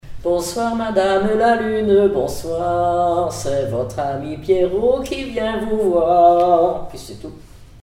Localisation Île-d'Yeu (L')
enfantine : berceuse
Comptines et formulettes enfantines
Pièce musicale inédite